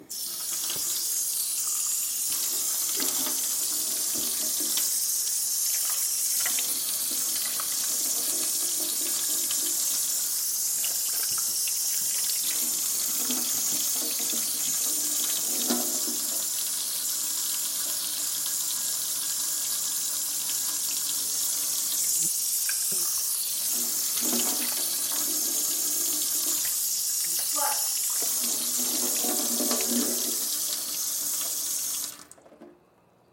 环境 " C3酒吧的人群
描述：用ZOOM F4和AT385b录制，用于电影最深的恐惧
标签： 水槽 漏极 浴室 水龙头
声道立体声